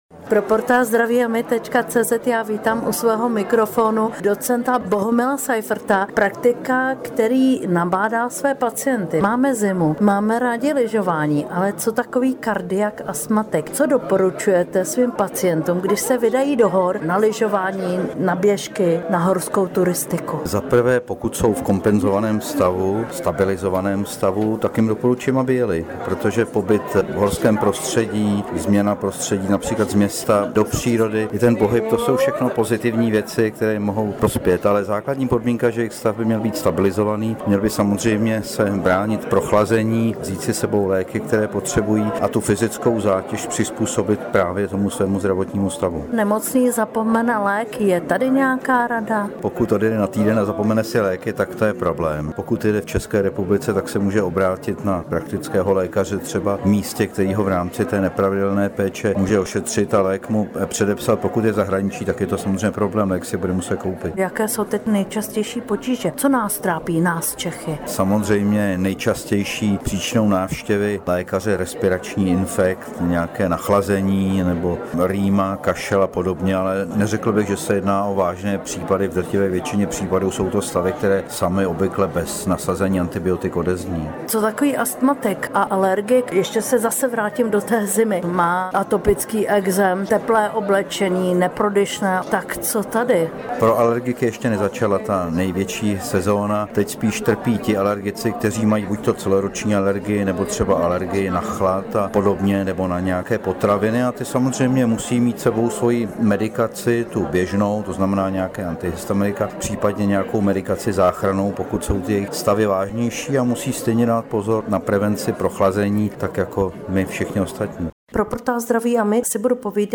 Rozhovor s praktickými lékaři, jak se bránit nachlazení a chřipce
Ptali jsme se praktických lékařů, jak se bránit nachlazení a chřipce.